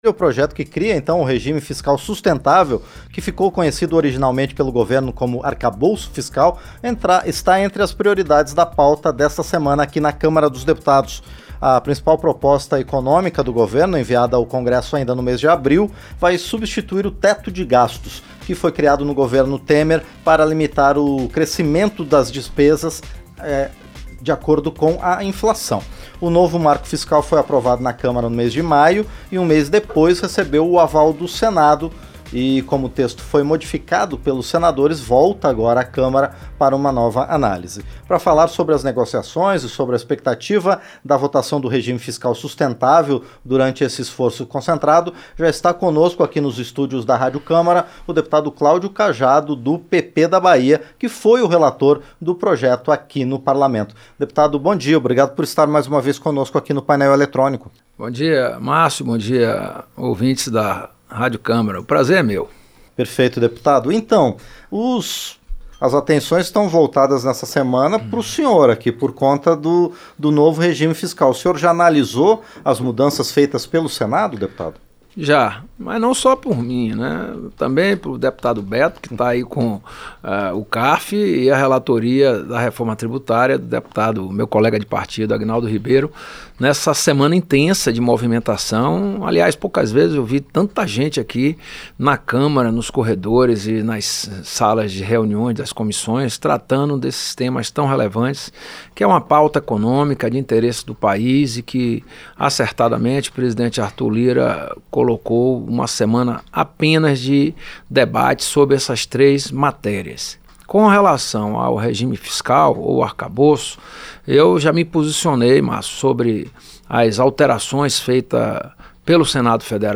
Entrevista - Dep. Cláudio Cajado (PP-BA)